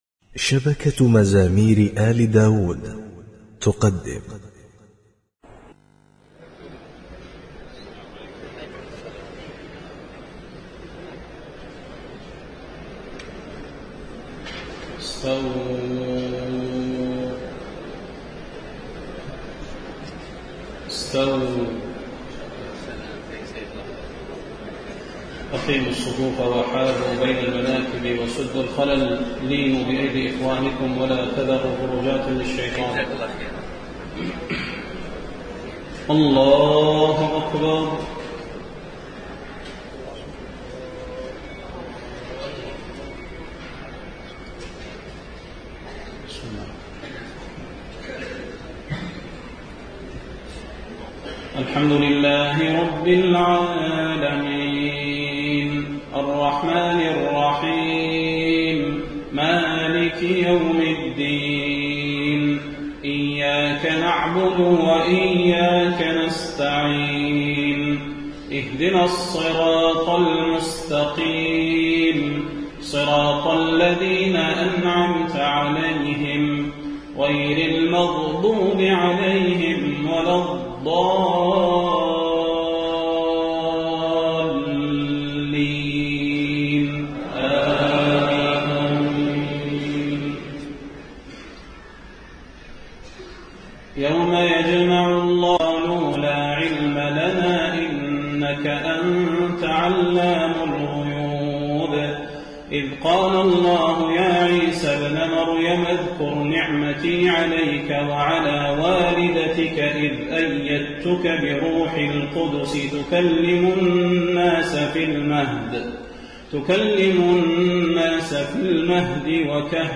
تهجد ليلة 26 رمضان 1432هـ من سورتي المائدة (109-120) و الأنعام (1-111) Tahajjud 26 st night Ramadan 1432H from Surah AlMa'idah and Al-An’aam > تراويح الحرم النبوي عام 1432 🕌 > التراويح - تلاوات الحرمين